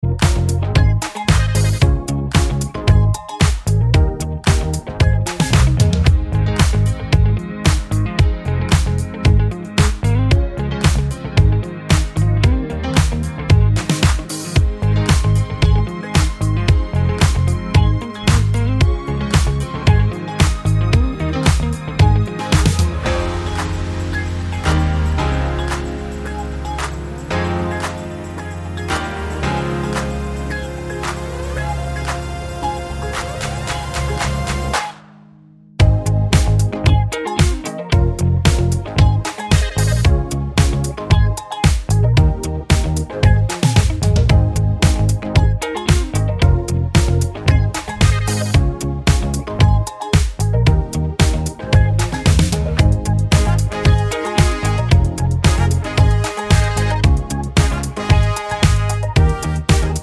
no Backing Vocals Pop (2020s) 2:48 Buy £1.50